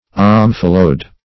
Search Result for " omphalode" : The Collaborative International Dictionary of English v.0.48: Omphalode \Om"pha*lode\, n. [Omphalo- + Gr. e'i^dos form.] (Bot.) The central part of the hilum of a seed, through which the nutrient vessels pass into the rhaphe or the chalaza; -- called also omphalodium .